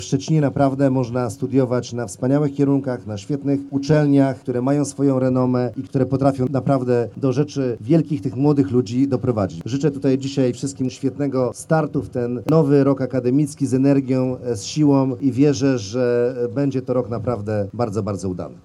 Za nami Spacerek na uniwerek, czyli uroczysty przemarsz rektorów i studentów wszystkich szczecińskich uczelni.